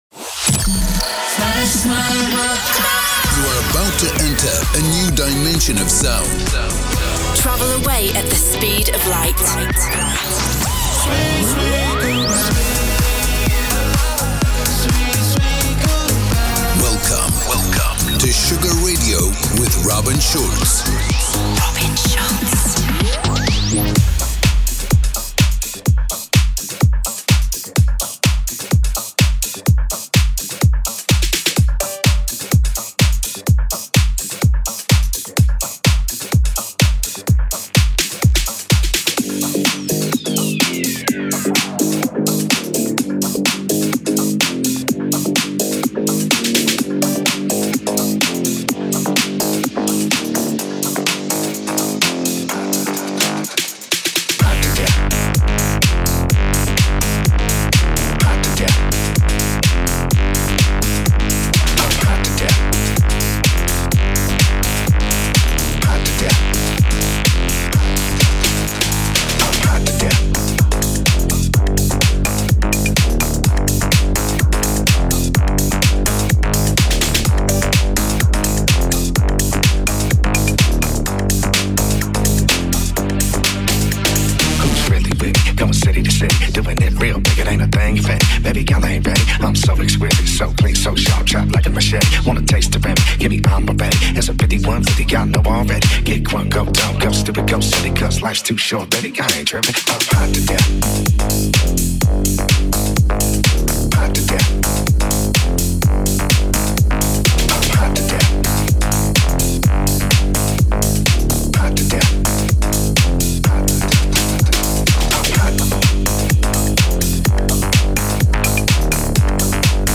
Genre: Electro Pop